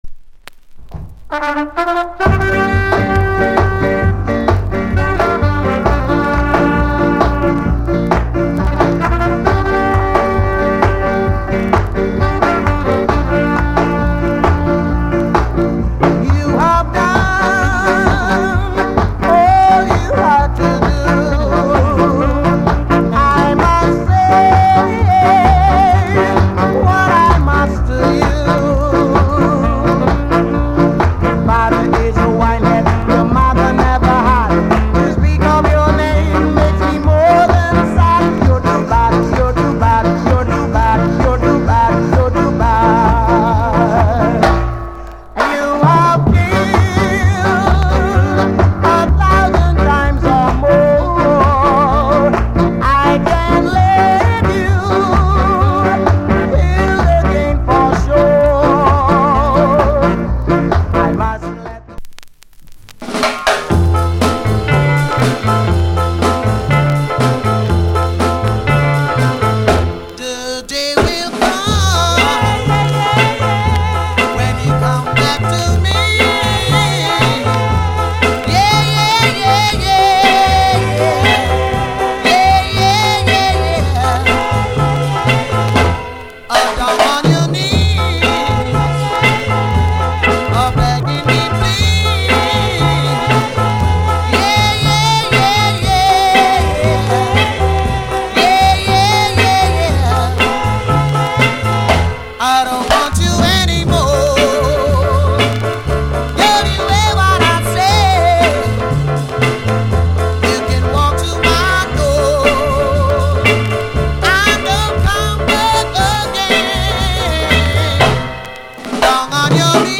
Genre [A] Ska [B] Others / Male Vocal
side-B 渋いR&Bチューン。